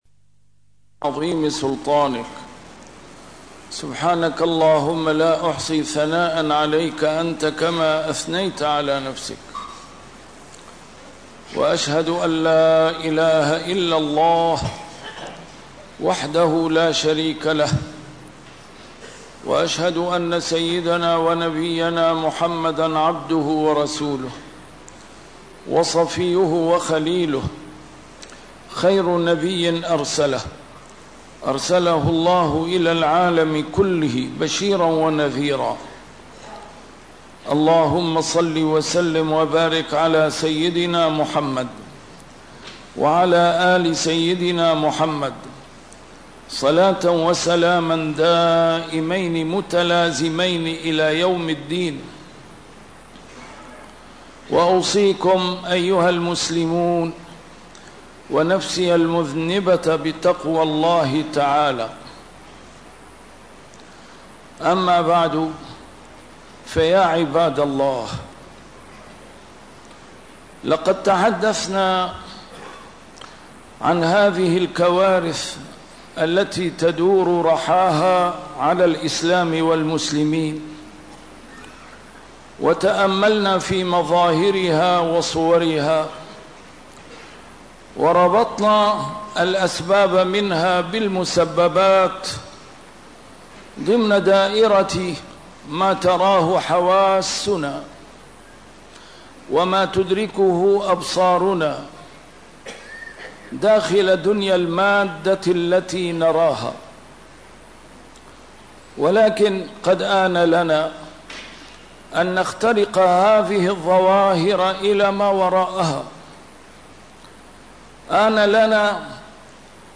نسيم الشام › A MARTYR SCHOLAR: IMAM MUHAMMAD SAEED RAMADAN AL-BOUTI - الخطب - أهيب بشعوب العالم الإسلامي أن يزدادوا إقبالاً على أعتاب الله